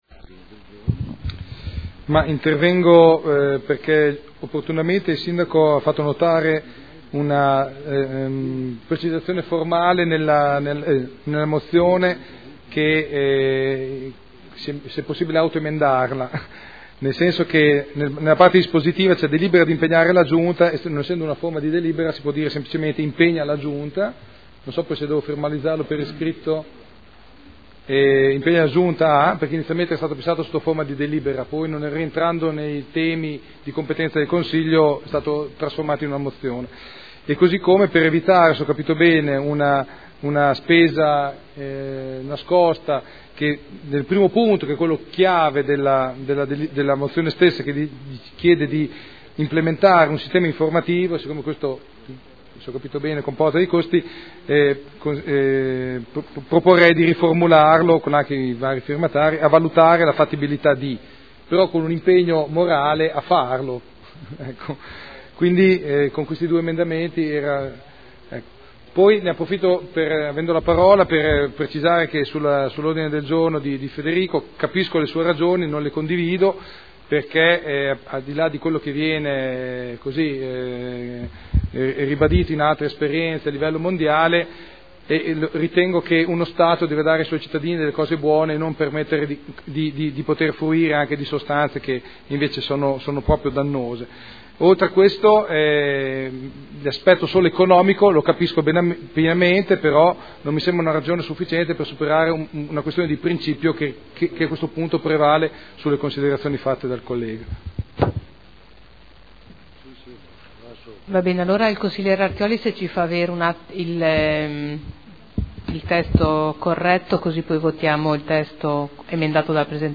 Seduta del 25/03/2013.